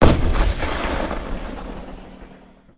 Kryzantema ATGM & MSTA-S artillery
I think the ATGM is slightly hollow sounding, and the artillery is too distant sounding, but they could be useful.